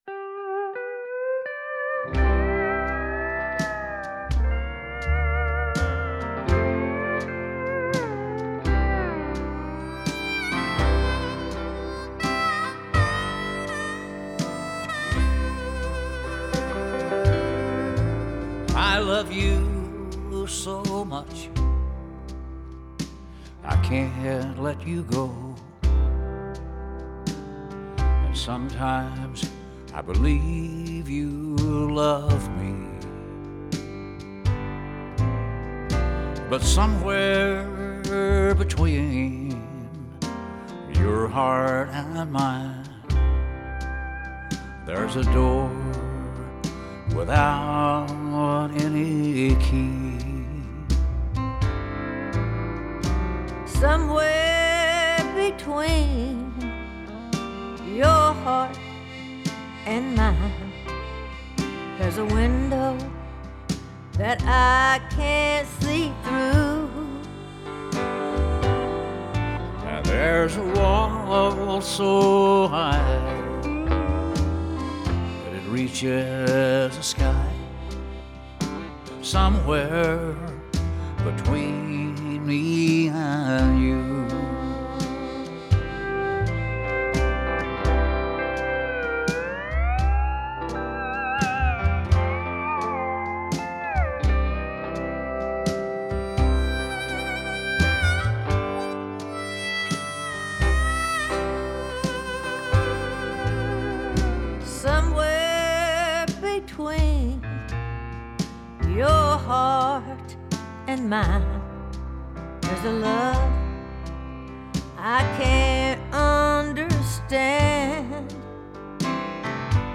I interviewed Hays twice over several decades about his work to preserve the Rock Island bridge over the Arkansas River, which at one point was slated to be torn down. Below is audio and a transcript of our final interview, which also delved into his experiences working as a Missouri Pacific fireman and brakeman while in college.